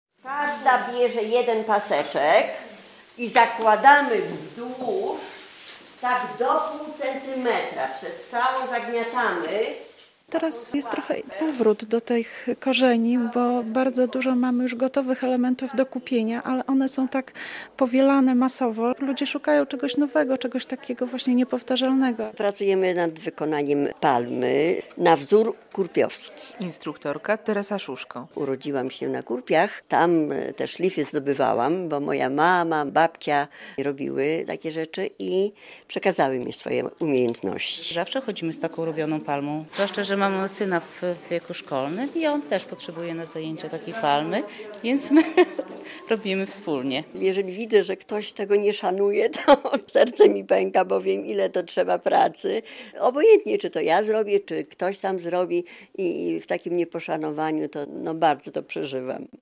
Warsztaty w WOAK-u - relacja